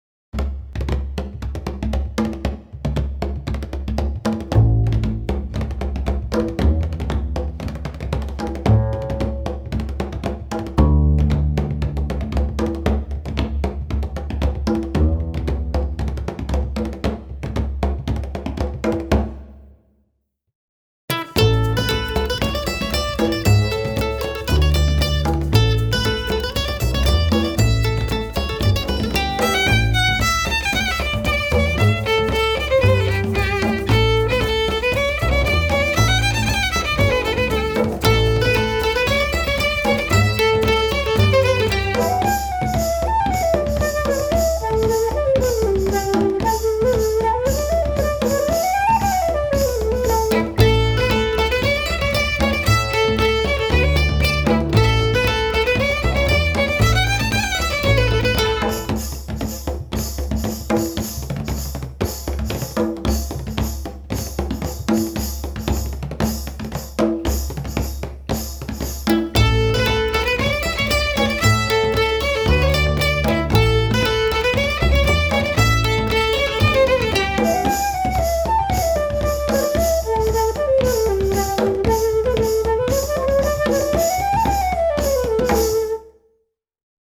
Mandolin, Flute, Guitar, Bass, Percussion.